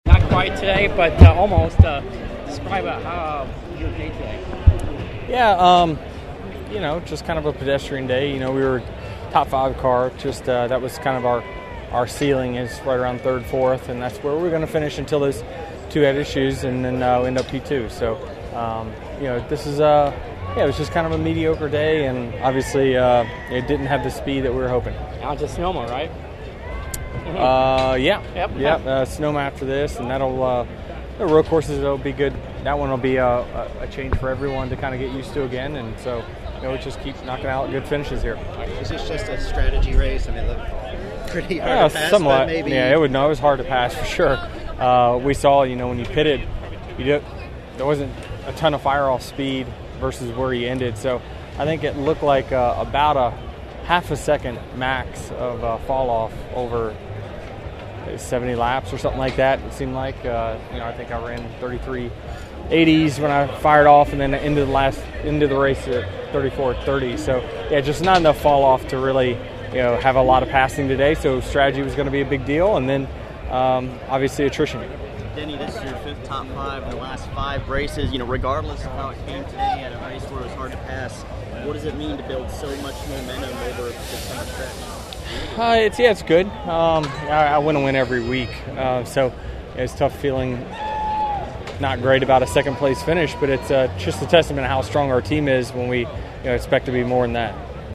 NASCAR Enjoy Illinois 300 Runner Up Denny Hamlin Post-Race RAW